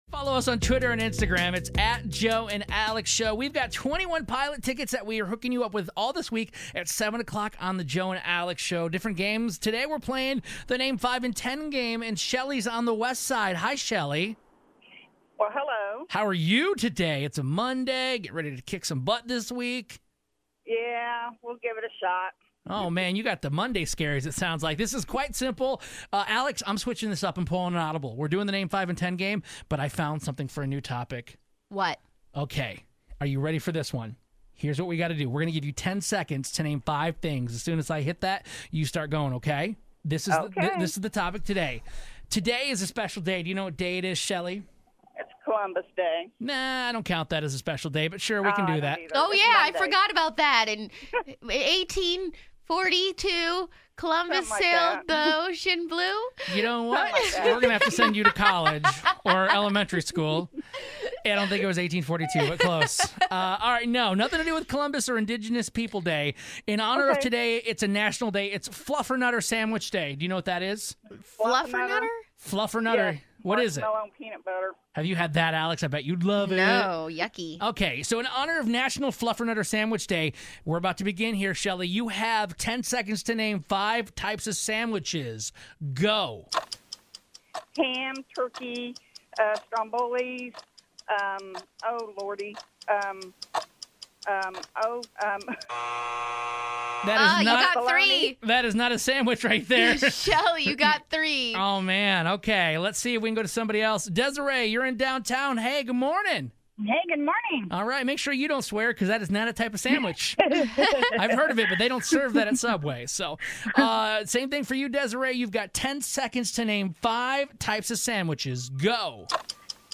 We have listeners call in and and try to name 5 sandwiches in 10 seconds to win 21 Pilot tickets.